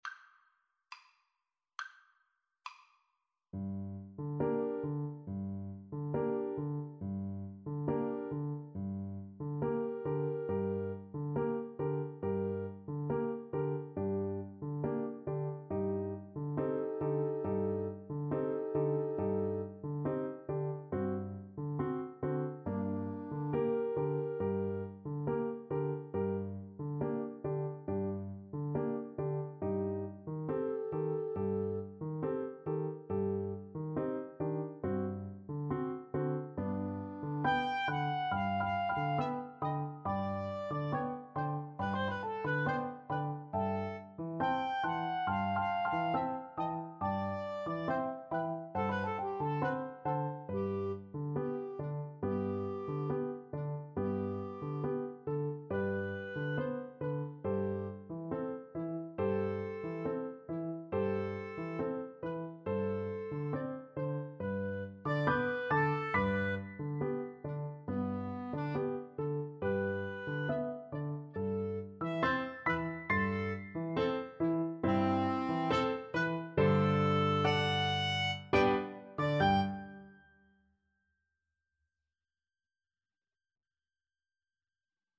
Clarinet 1Clarinet 2Piano
Classical (View more Classical Clarinet Duet Music)